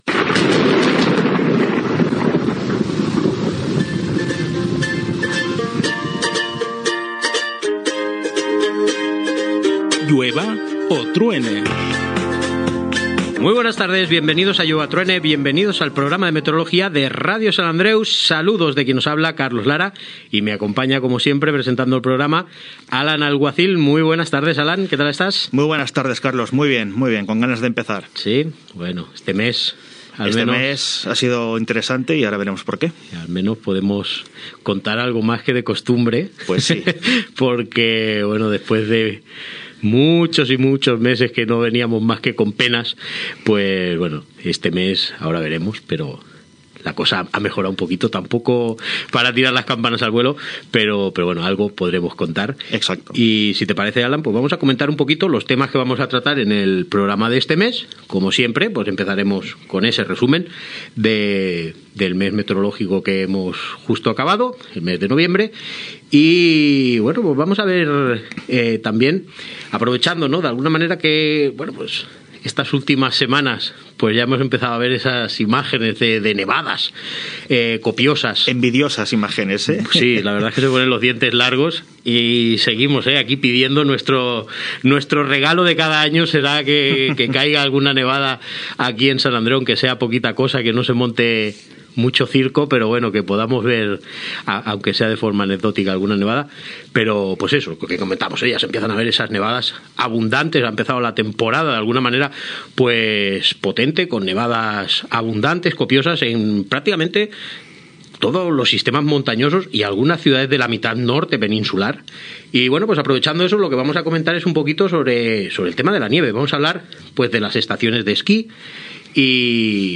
Careta del programa, presentació de l'espai sobre meteorologia amb el sumari. Resum de les temperatures del mes de novembre.
Divulgació